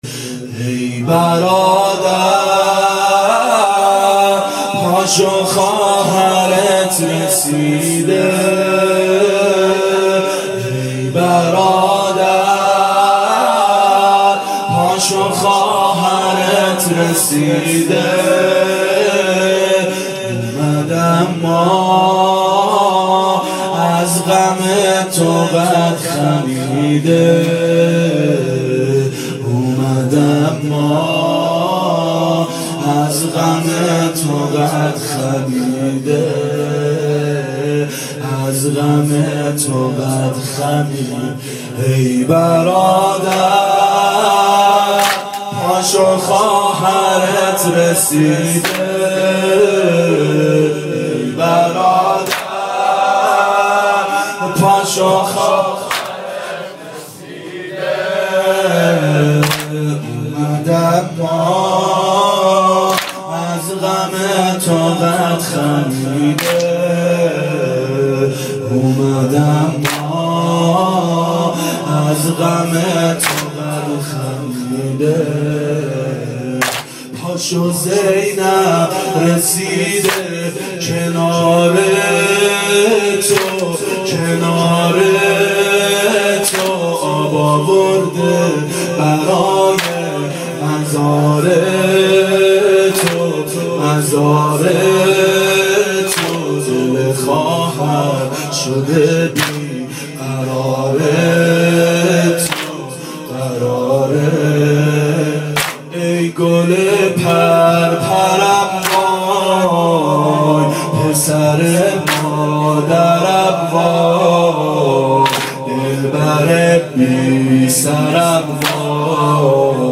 • ظهر اربعین سال 1390 محفل شیفتگان حضرت رقیه سلام الله علیها